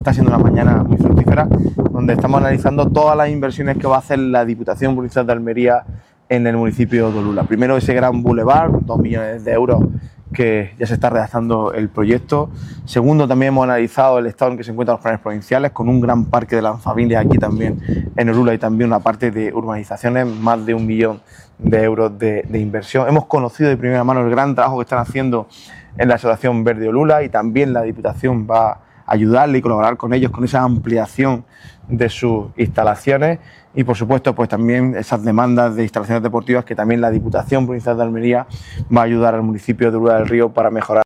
19-03_vsita_olula_del_rio__jose_antonio_garcia__presidente.mp3